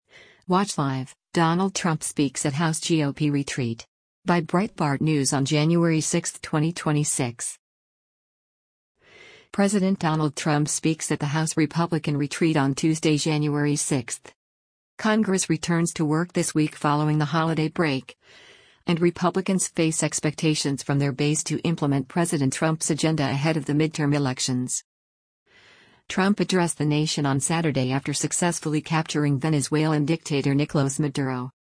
President Donald Trump speaks at the House Republican retreat on Tuesday, January 6.